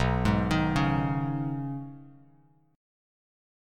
B6add9 chord